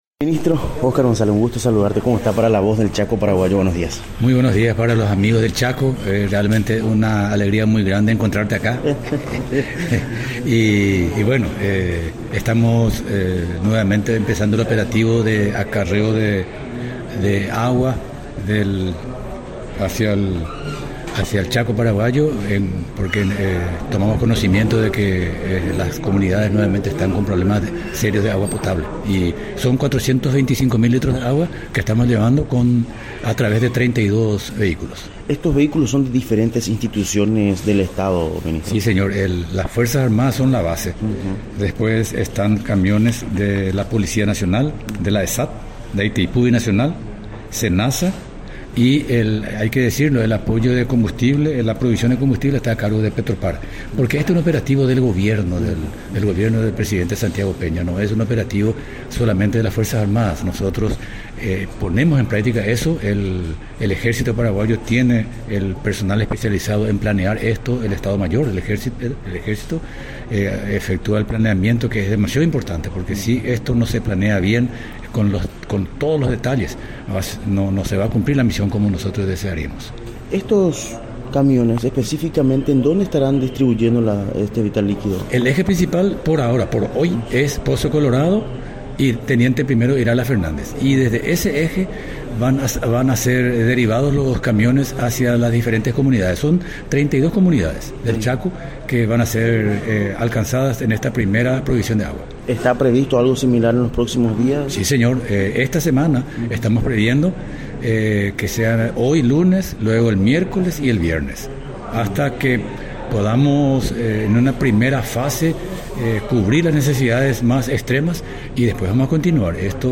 Entrevistas / Matinal 610
Entrevistado: Oscar González, Arsenio Zárate
Estudio Central, Filadelfia, Dep. Boquerón